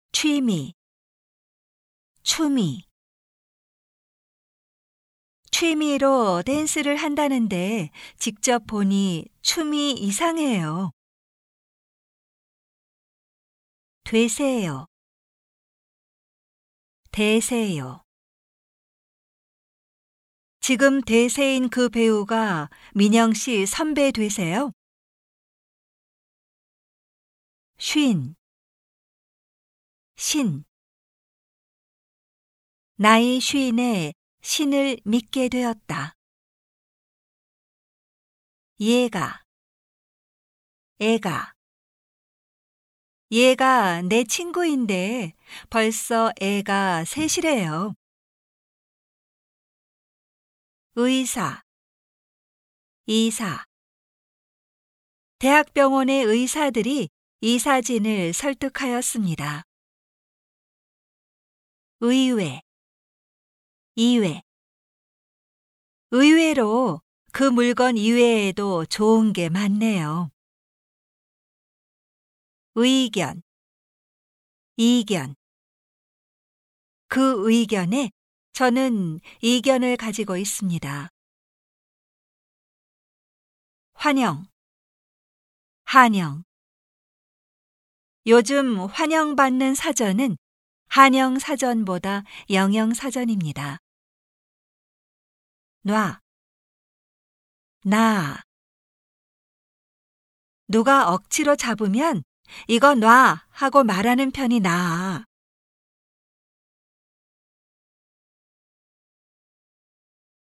※正しい発音のみ収録しています